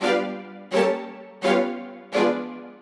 Index of /musicradar/gangster-sting-samples/85bpm Loops
GS_Viols_85-G.wav